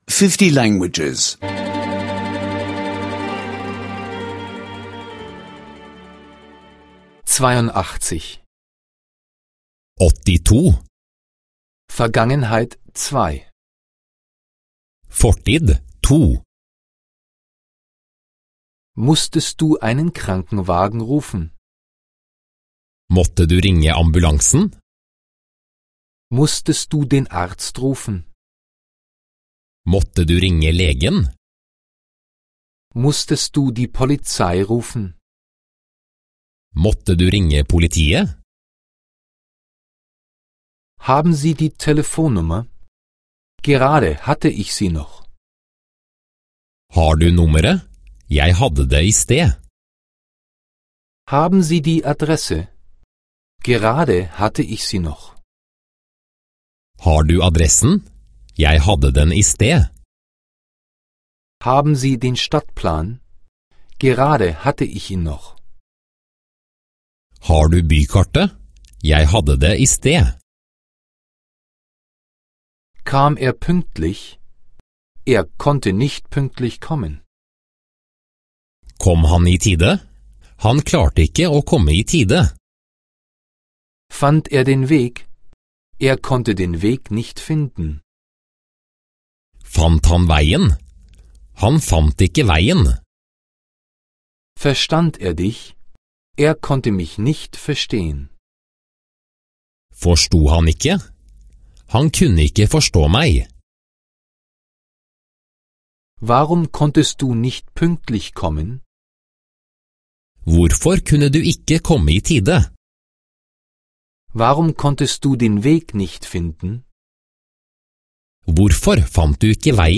Norwegisch Sprache-Audiokurs (kostenloser Download)